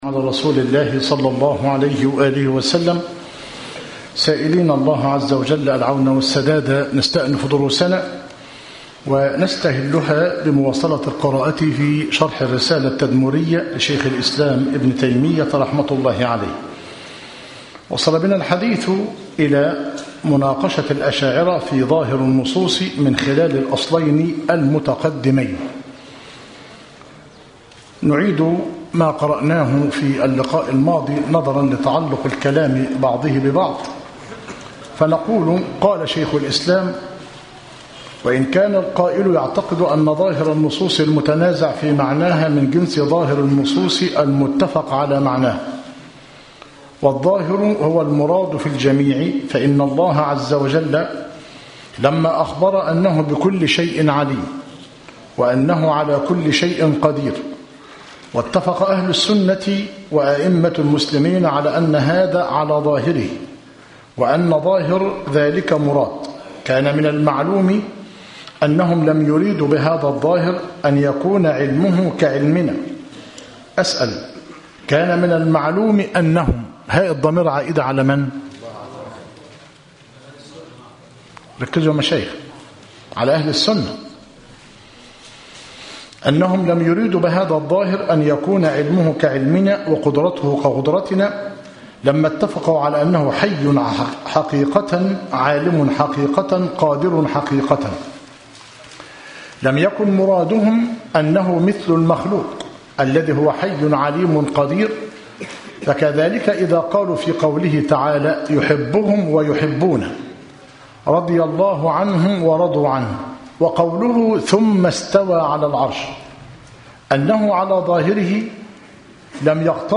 التعليق على كتاب التوضيحات الأثرية لمتن الرسالة التدمرية جمع وترتيب فخر الدين بن الزبير بن علي المحسَي - مسجد التوحيد - ميت الرخا - زفتى - غربية - المحاضرة السابعة والعشرون - بتاريخ 3- جماد أول - 1437هـ الموافق 12 - فبراير - 2016 م